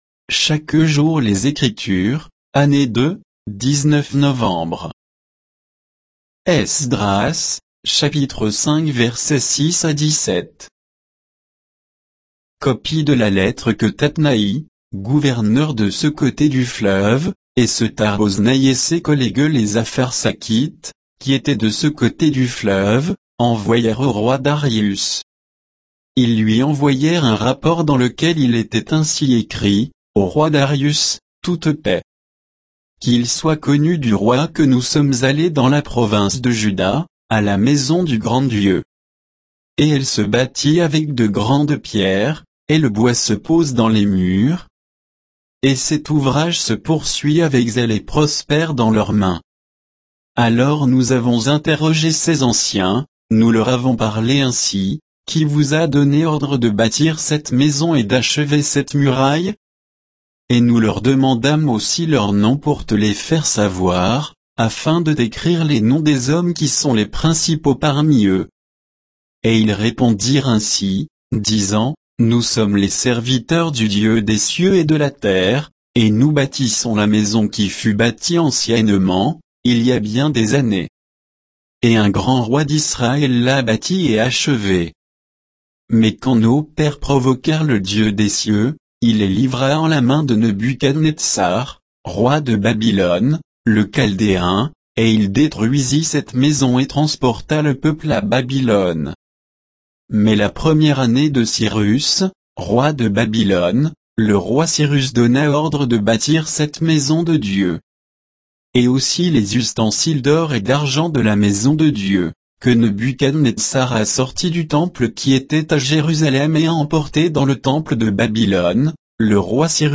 Méditation quoditienne de Chaque jour les Écritures sur Esdras 5